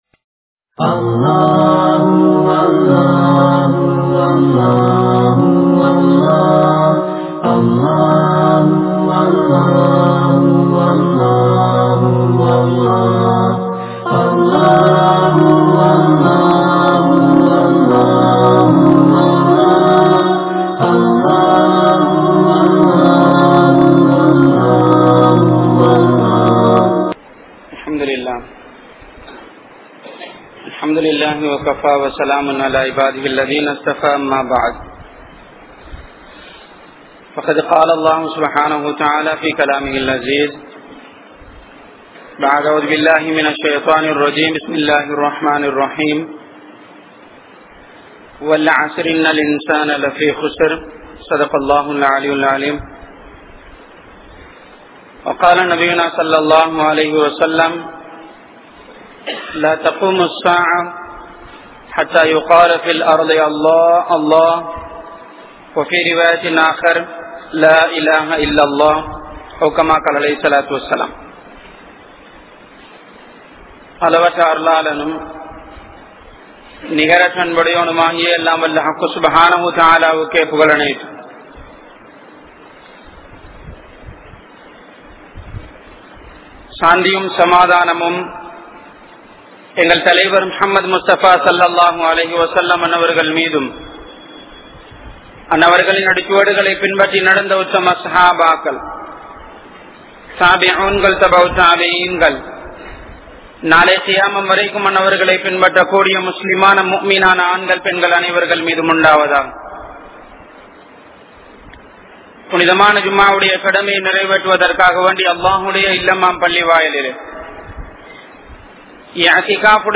Islathai Vittu Matham Maarum Muslimkal (இஸ்லாத்தை விட்டு மதம் மாறும் முஸ்லிம்கள்) | Audio Bayans | All Ceylon Muslim Youth Community | Addalaichenai
Kanampittya Masjithun Noor Jumua Masjith